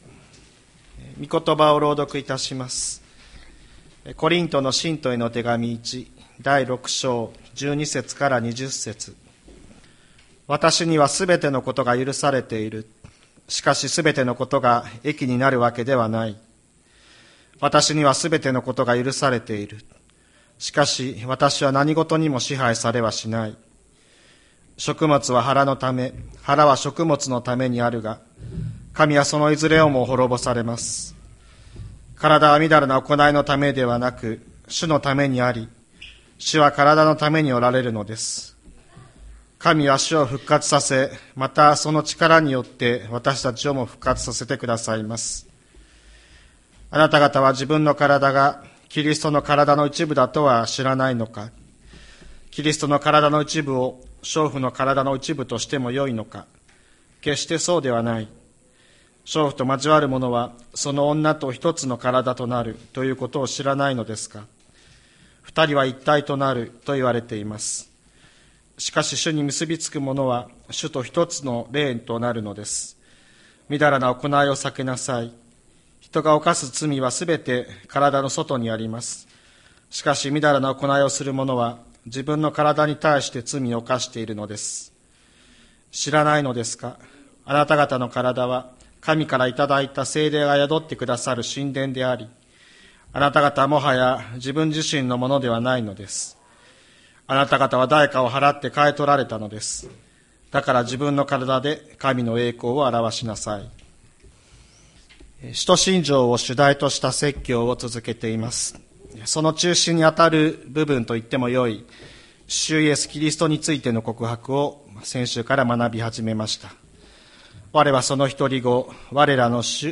2024年02月11日朝の礼拝「イエスを主と呼び」吹田市千里山のキリスト教会
千里山教会 2024年02月11日の礼拝メッセージ。